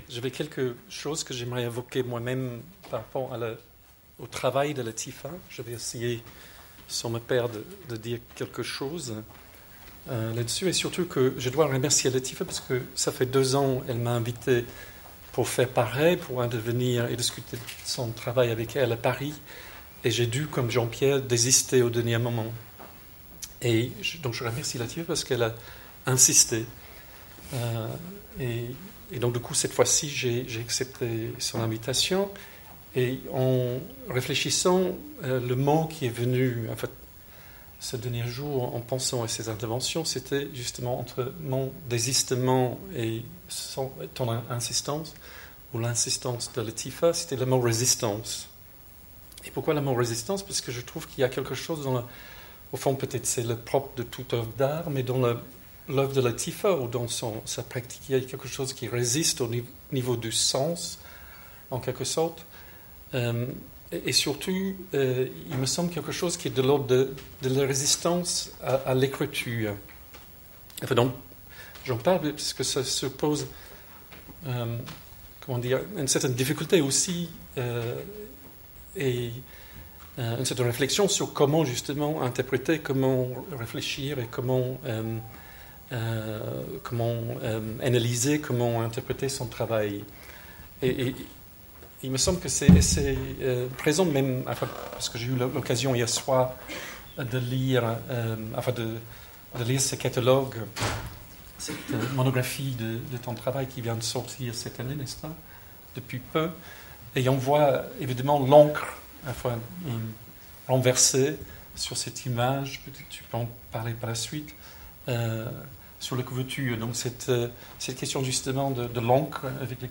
Ecouter la captation sonore de la rencontre (durée : 1h21)